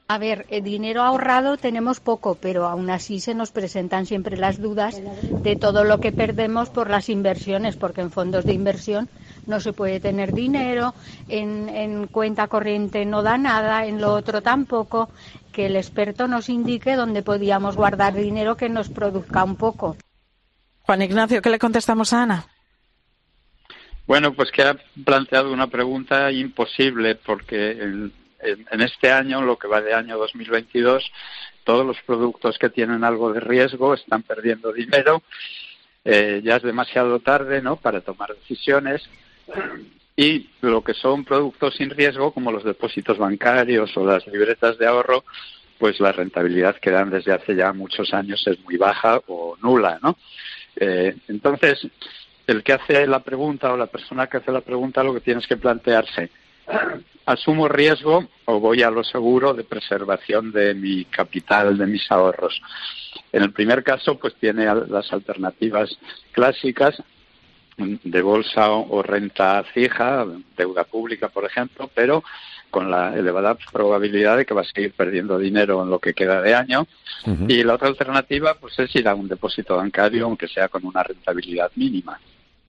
El experto responde a la pregunta más recurrente de los oyentes de "Herrera en COPE"